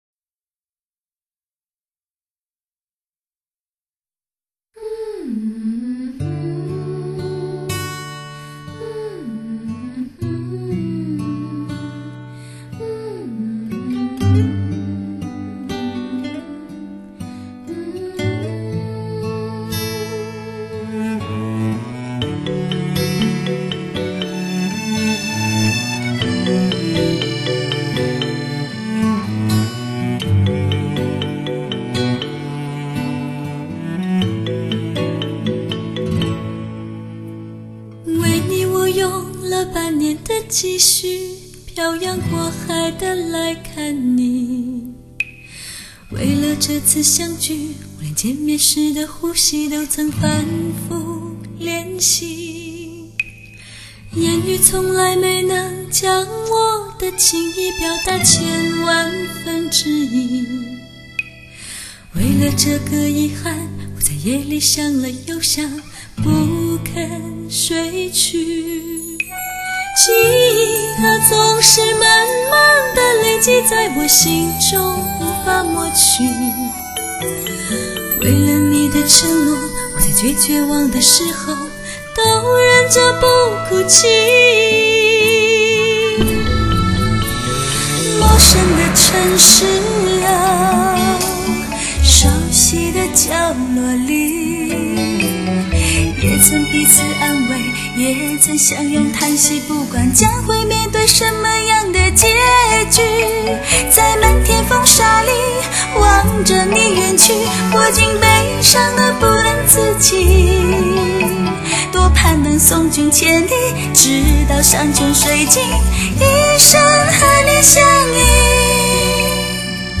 一张跨年度制作的环绕声音乐大碟
女子三重唱
还用到顶级真空管对音质进行品质处理